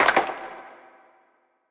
Perc 2.wav